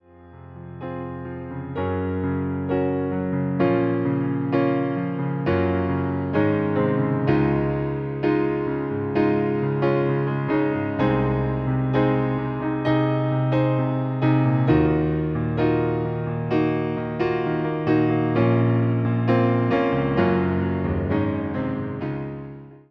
Podkład fortepianowy
Wersja demonstracyjna:
65 BPM
Cis – dur